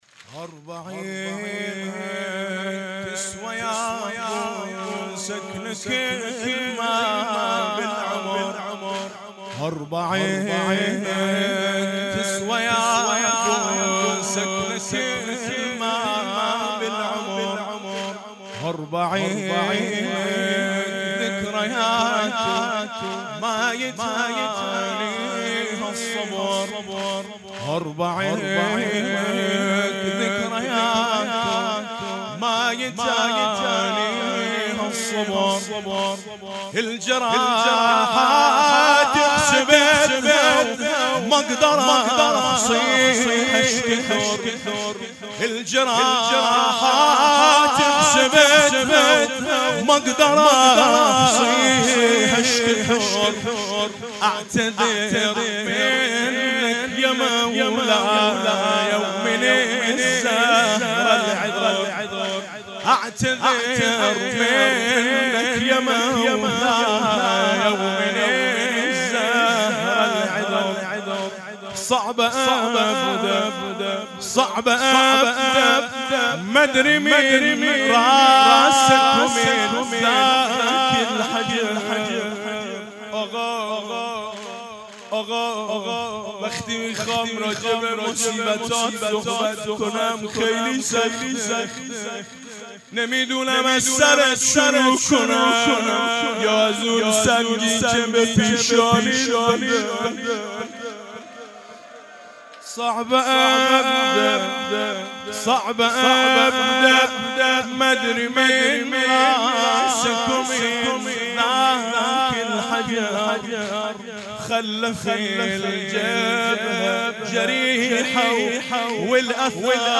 بشنوید/ مداحی حاج میثم مطیعی در مسجد حنانه (نجف اشرف)